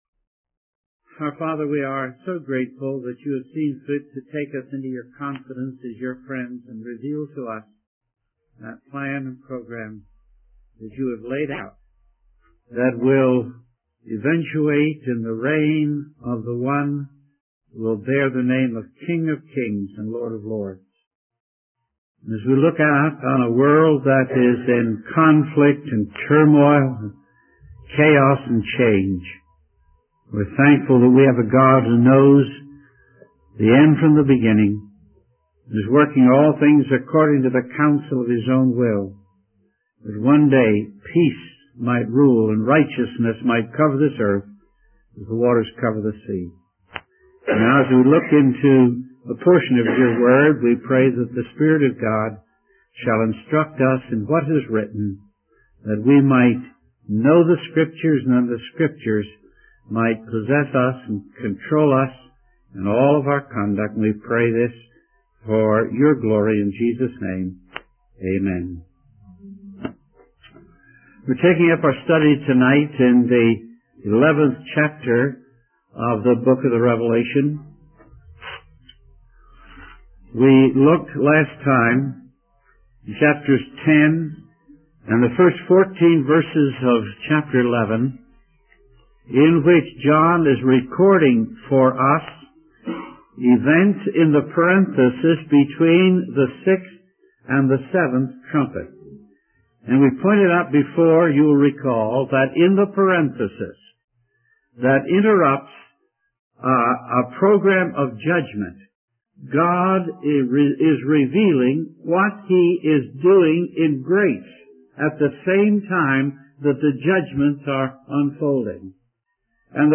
In this sermon, the speaker focuses on the events described in the eleventh chapter of the book of Revelation. He explains that during the time of judgment, God sends two prophets to call the people of Israel to repentance and warn them of the coming judge.